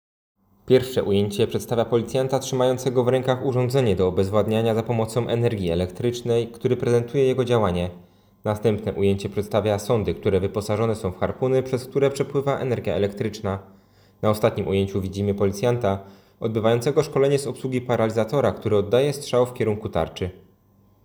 Opis nagrania: Audiodeskrypcja filmu